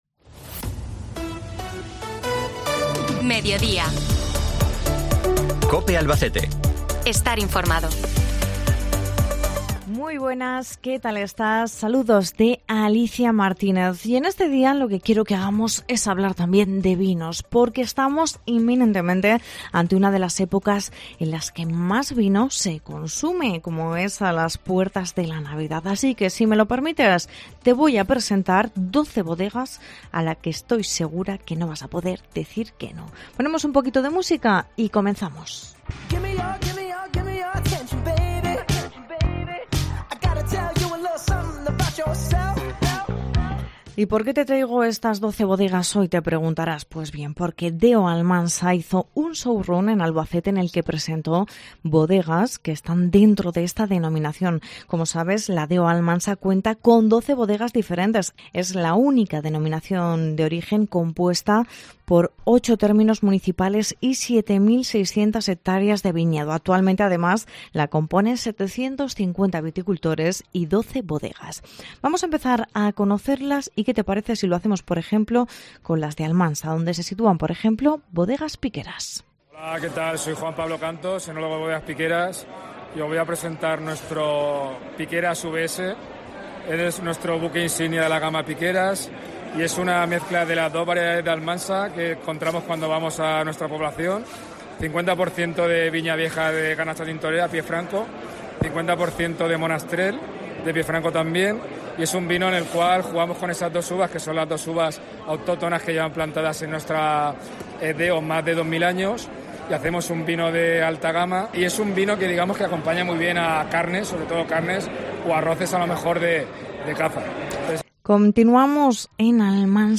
Reportaje Bodegas DO Almansa